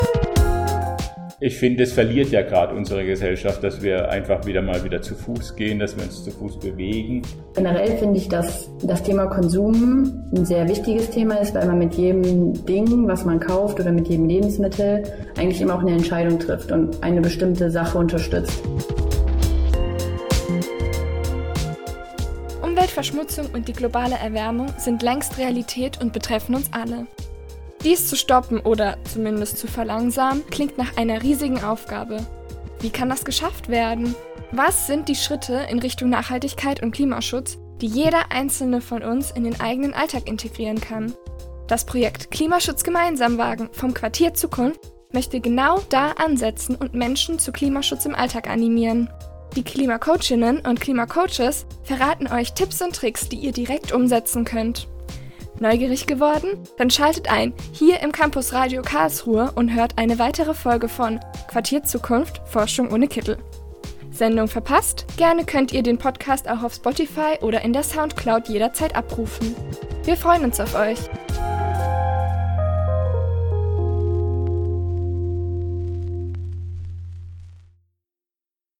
Audioteaser-KlimaschutzGemeinsamWagen.mp3